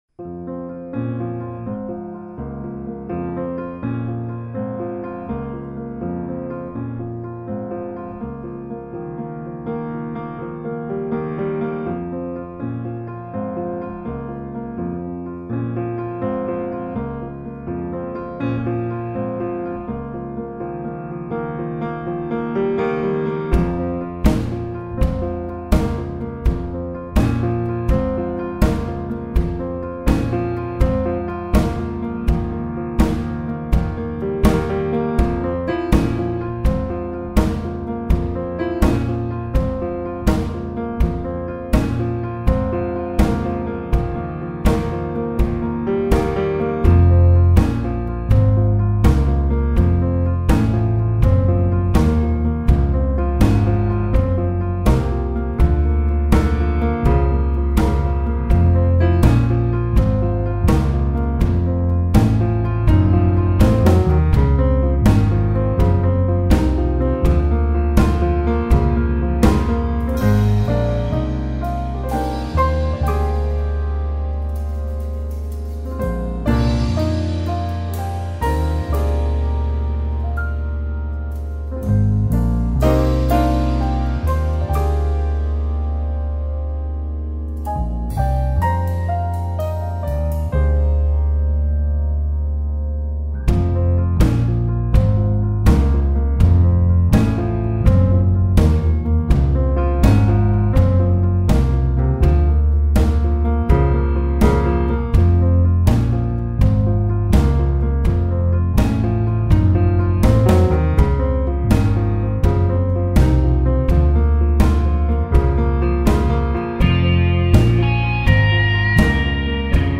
Klavir
Bubanj
Gitara